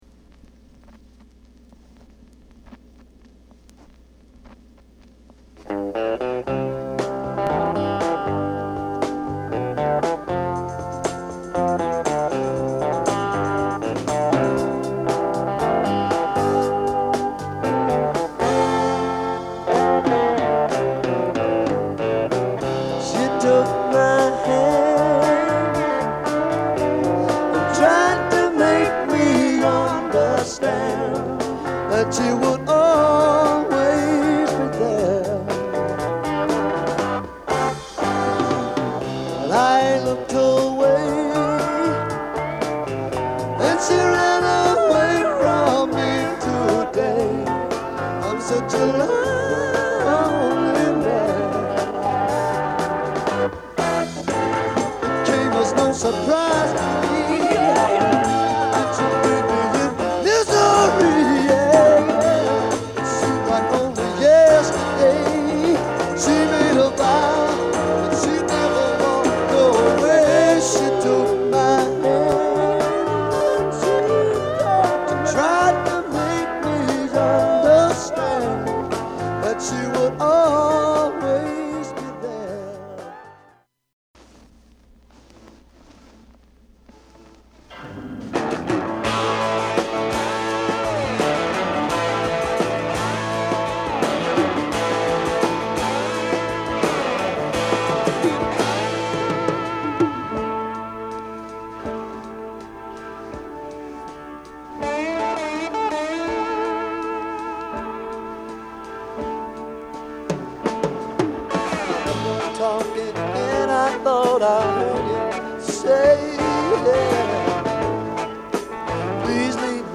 ROCK / BRITISH ROCK / BLUES
盤は薄い擦れや僅かですが音に影響がある傷がいくつかある、使用感が感じられる状態です。